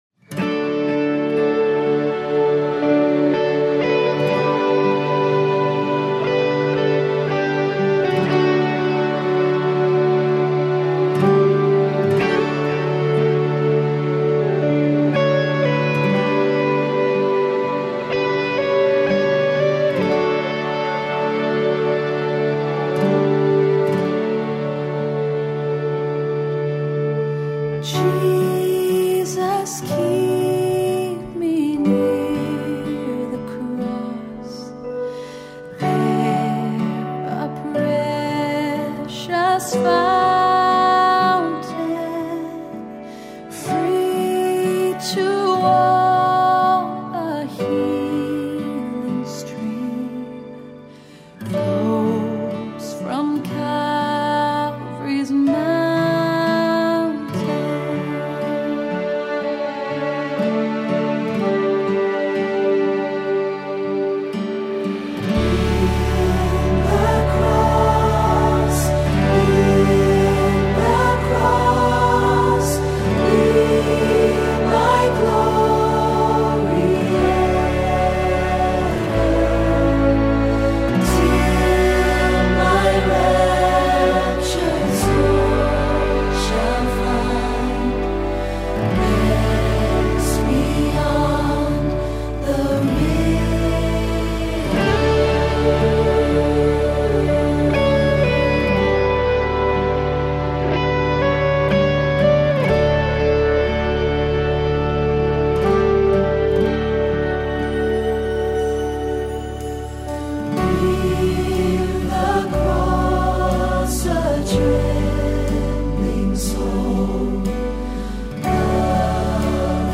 2 NEW upcoming anthems!